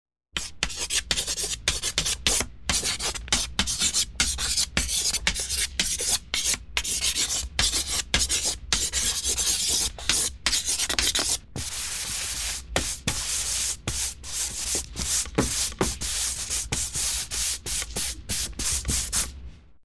Давича записал на студии Драмсы, и гитарки прописал.... как вообсче в целом материал и сам звук? если кому будет интересно отпишусь что и как и на чём...
Ну по крайней мере звучит как хорошая репетиция) Гитара понравилась, я бы правда верха срезал чуток А так классно, думаю с басом ваще качать будет.